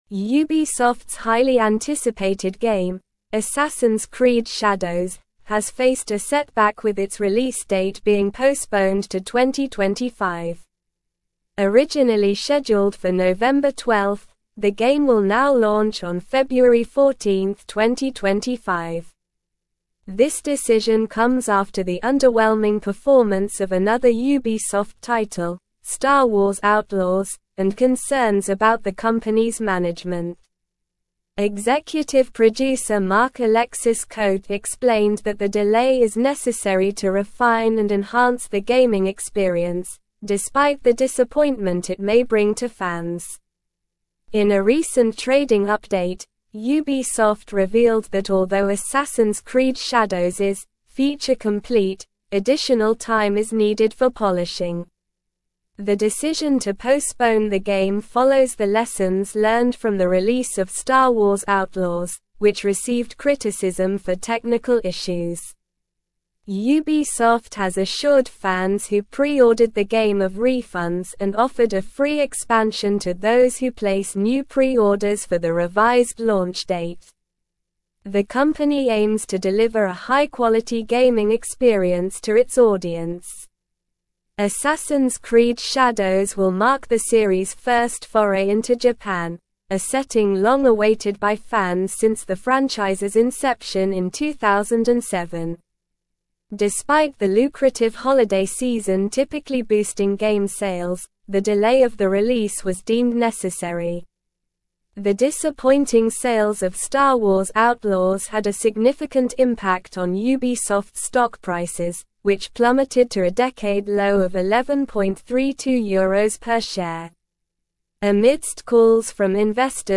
Slow
English-Newsroom-Advanced-SLOW-Reading-Ubisoft-Delays-Assassins-Creed-Shadows-Release-to-2025.mp3